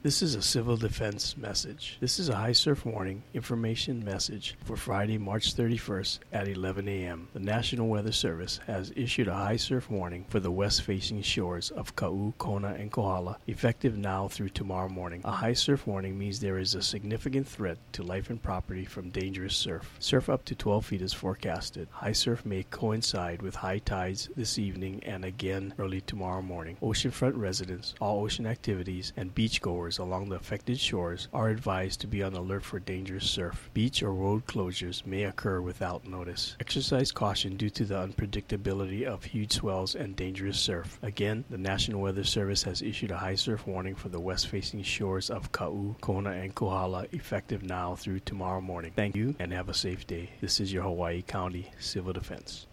At 11 a.m. the Hawaii County Civil Defense issued an alert message concerning the high surf warning.